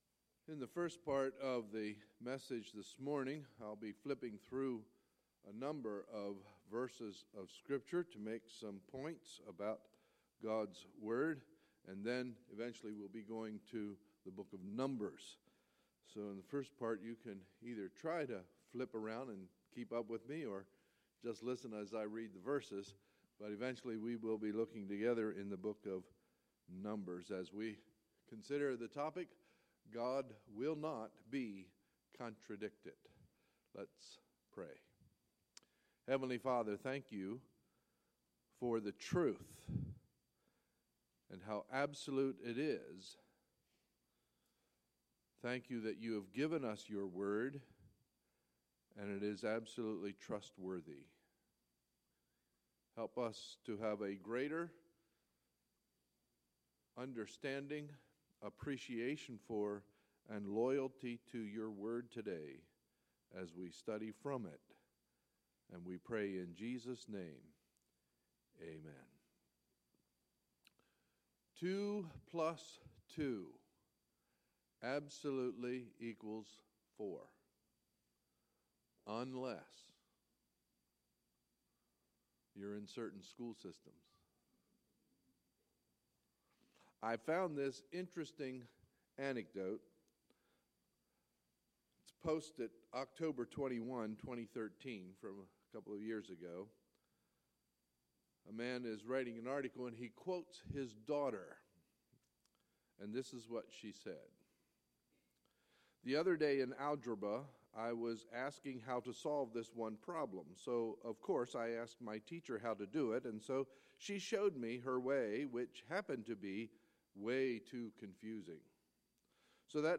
Sunday, July 24, 2016 – Sunday Morning Service